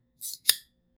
Smart/Automatic Gates open and close automatically 0:15 Moving Target Mechanism ``` Moving target mechanism sound, mechanical whirring, sliding, motorized movement, automated system 0:10 Lid opens automatically, quickly. 0:01
lid-opens-automatically-q-qy5vk5zc.wav